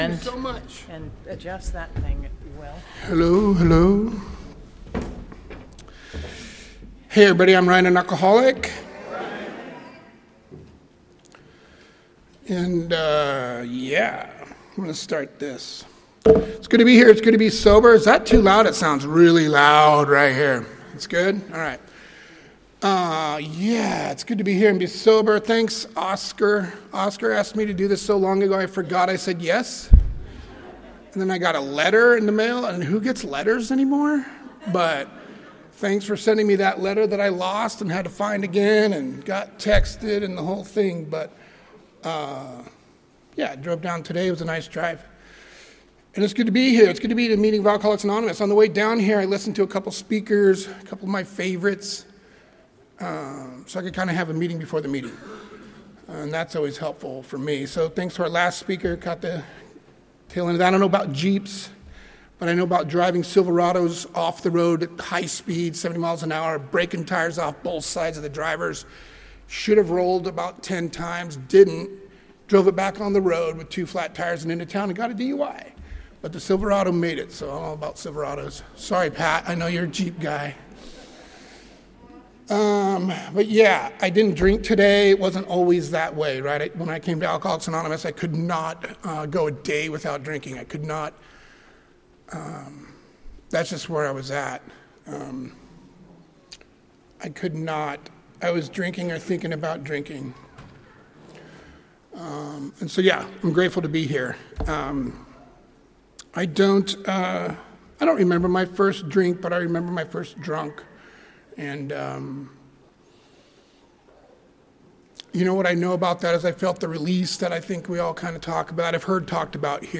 34th Annual Indian Wells Valley Roundup